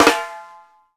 FLAM2     -L.wav